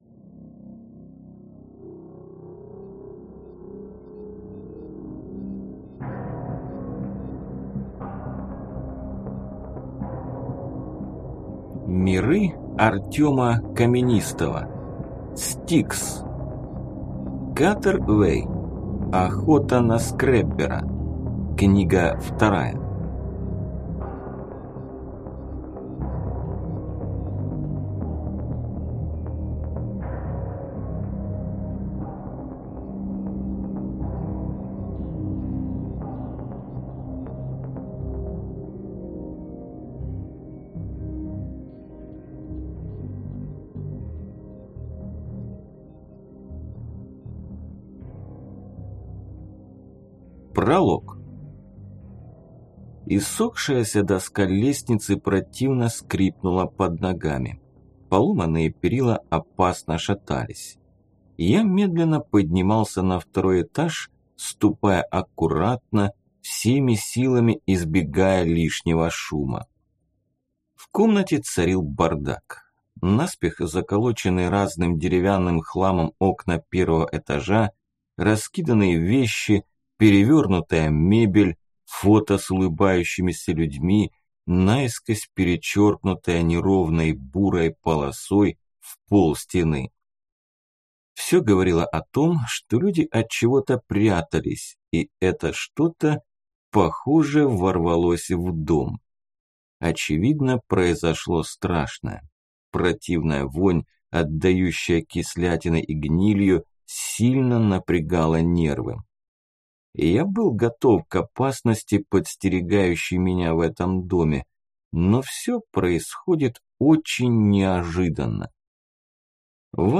Аудиокнига S-T-I-K-S. Охота на скреббера | Библиотека аудиокниг